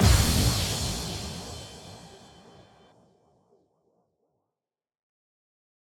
Impact 12.wav